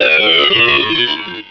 Cri de Regice dans Pokémon Rubis et Saphir.